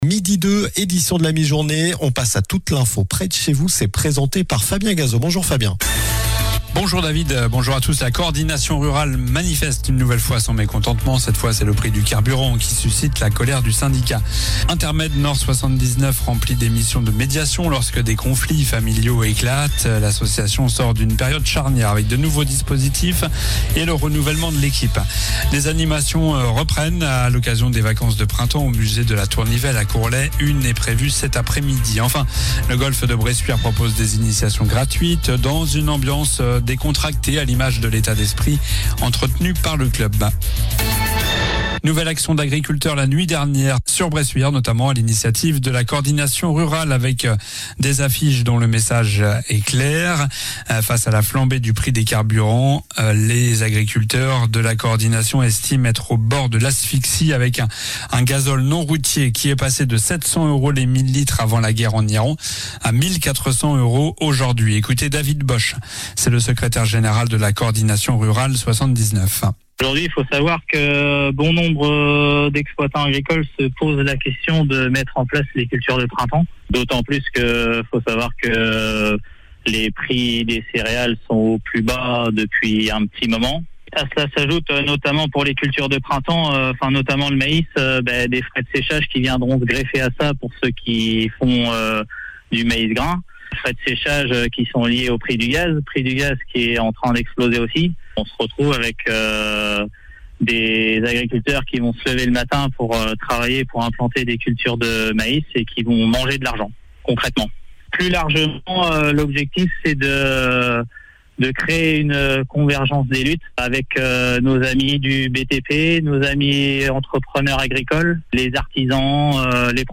Journal du mercredi 8 avril (midi)
L'info près de chez vous